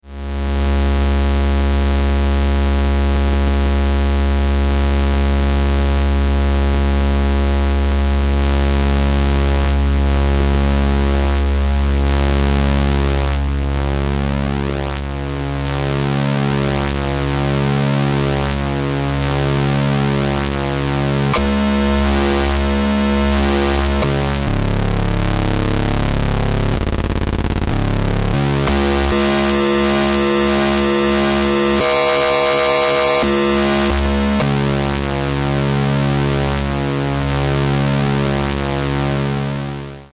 2台のA-111の矩形波同士によるリング・モジュレーション
a114ring.mp3